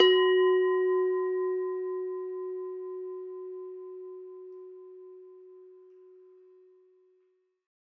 mono_bell_-8_F#_8sec
bell bells bell-set bell-tone bong ding dong ping sound effect free sound royalty free Sound Effects